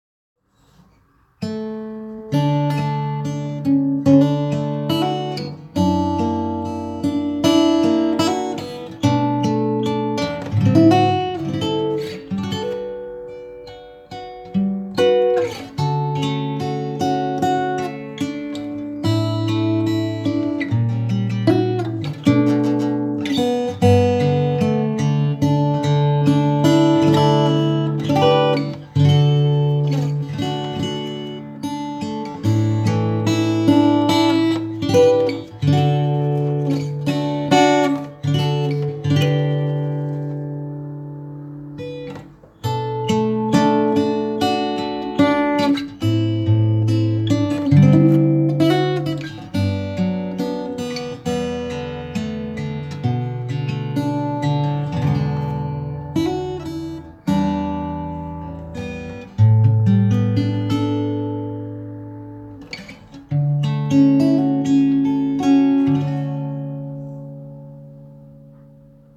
on guitar